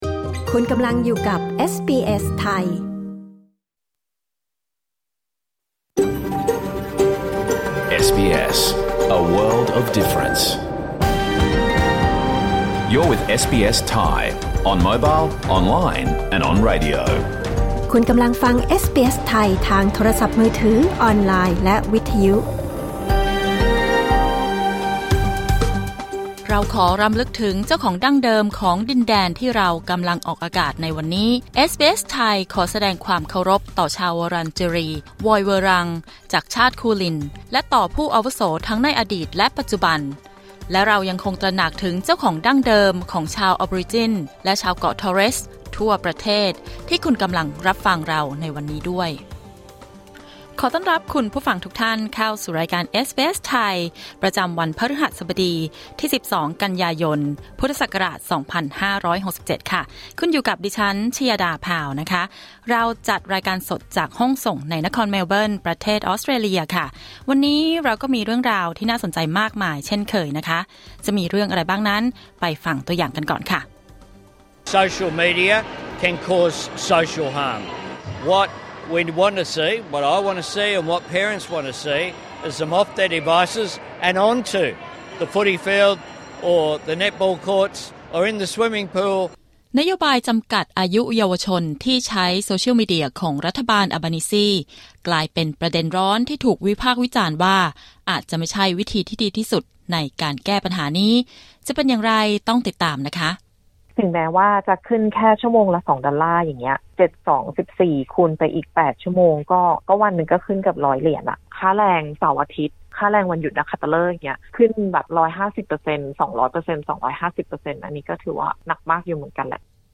รายการสด 12 กันยายน 2567